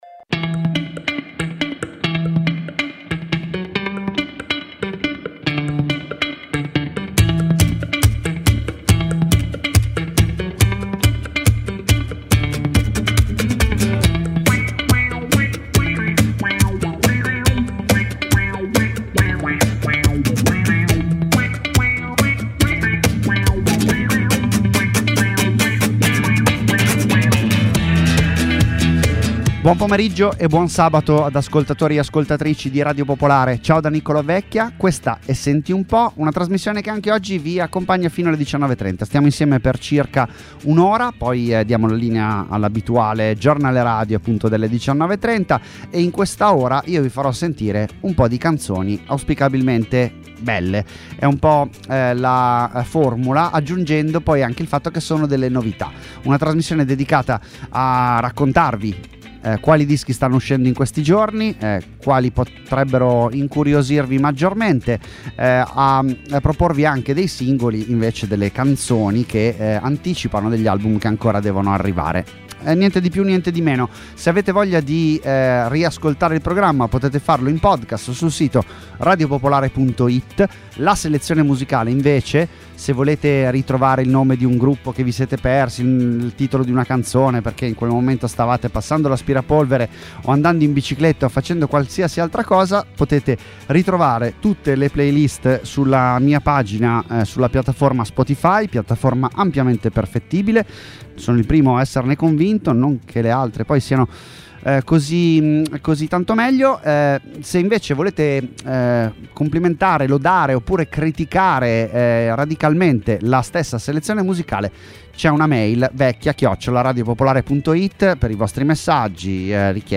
Ospiti, interviste, minilive, ma anche tanta tanta musica nuova. 50 minuti (circa…) con cui orientarsi tra le ultime uscite italiane e internazionali.